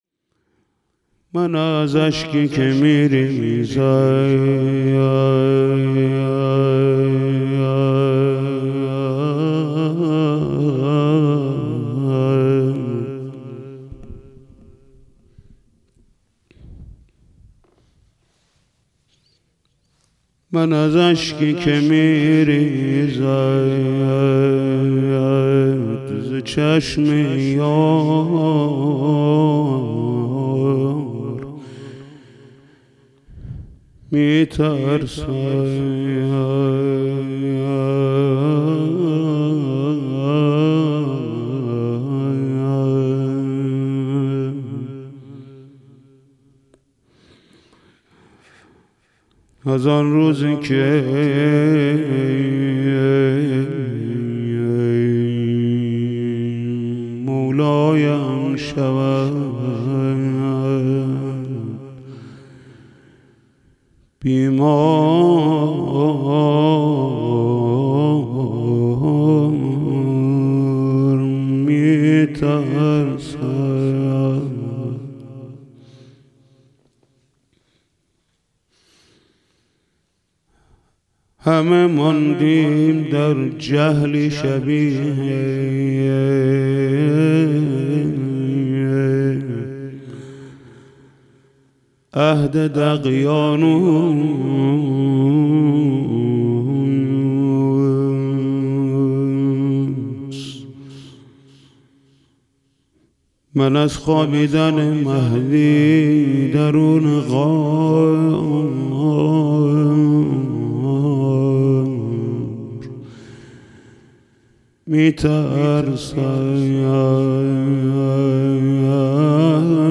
دسته : الکترونیک